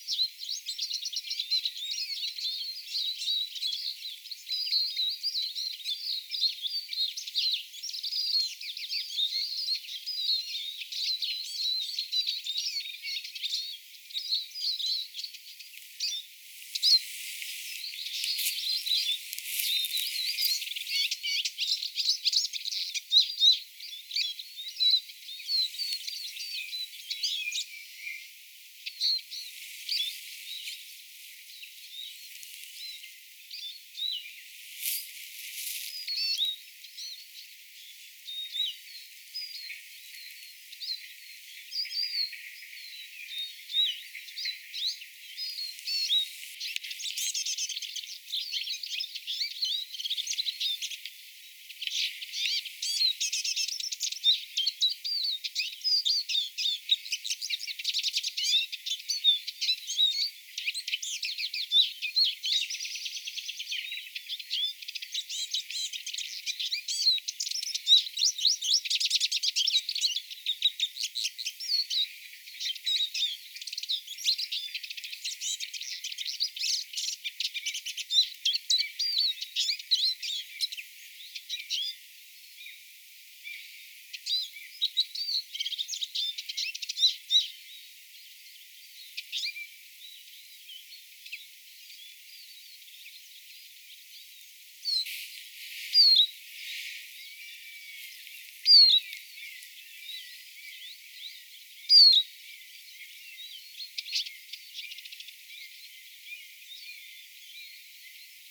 vihervarpusen laulua
vihervarpusen_laulua_innokasta.mp3